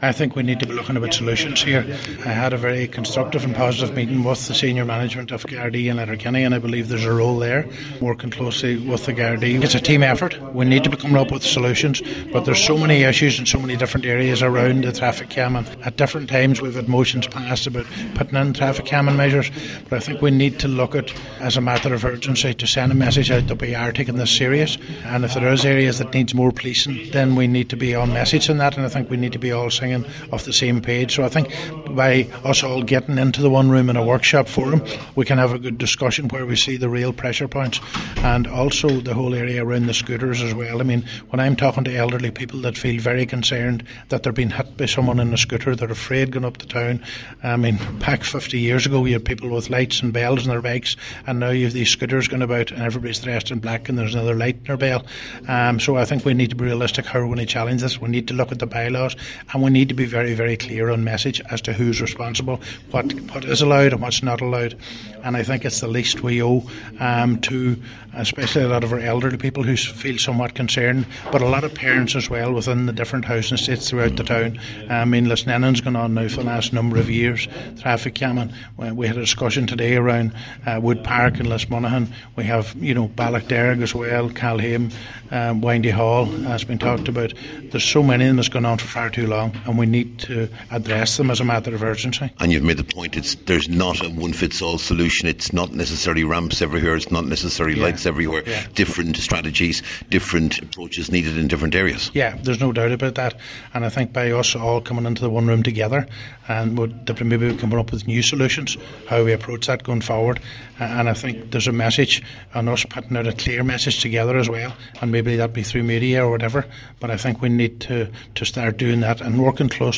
Cllr Brogan says the most important thing is that members and officials are seen to be singing off the same hymn sheet……..